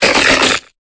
Cri de Mysdibule dans Pokémon Épée et Bouclier.